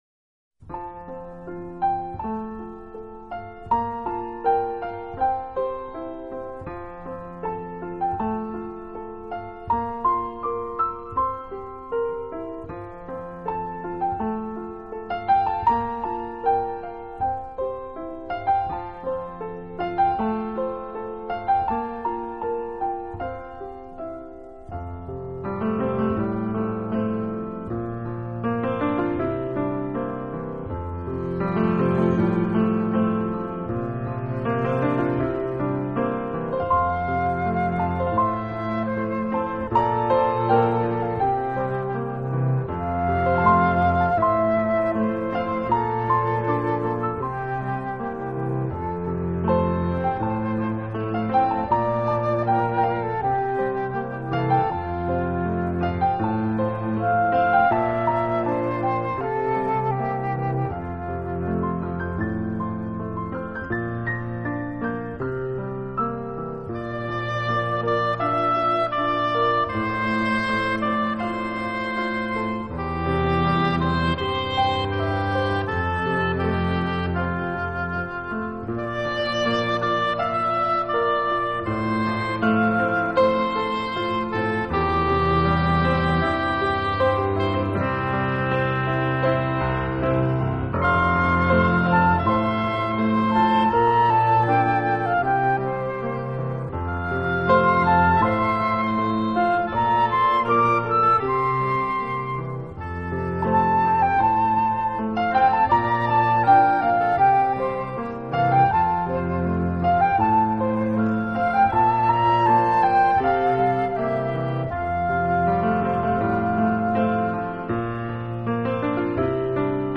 【纯音钢琴】